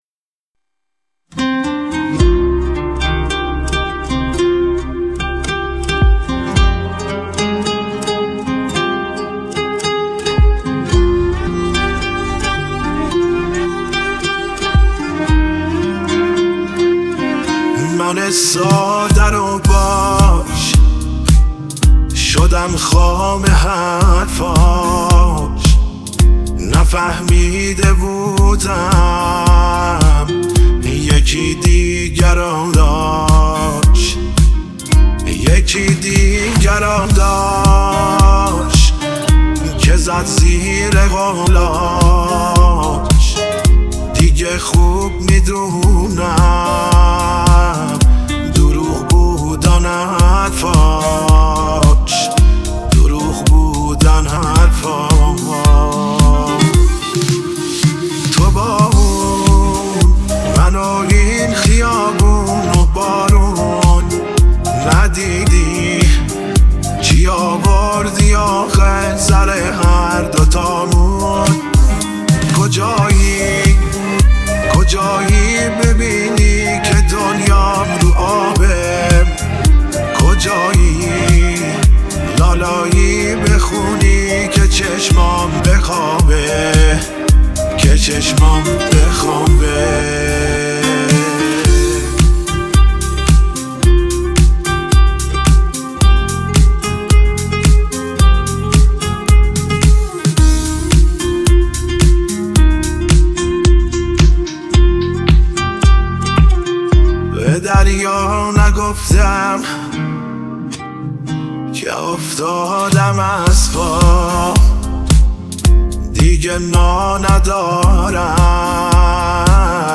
• Iran • 2 ماه پیش : مصنوعیه مشخصه